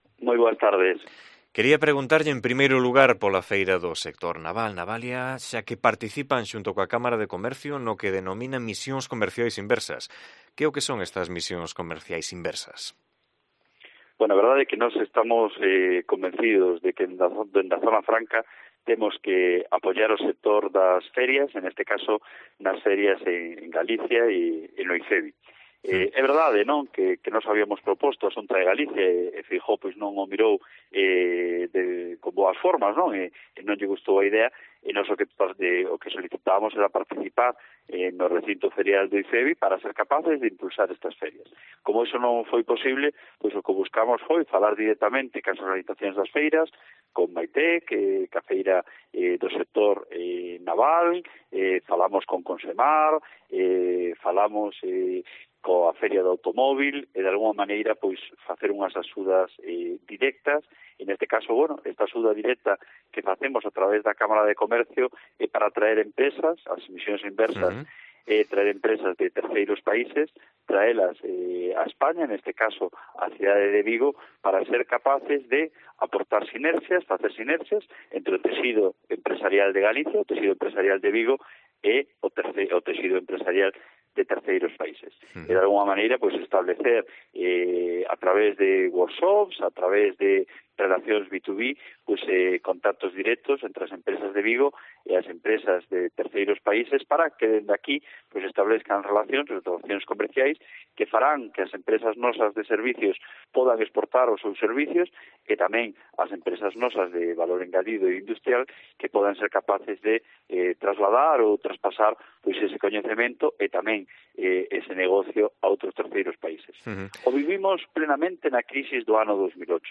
AUDIO: Entrevistamos al delegado de Zona Franca, David Regades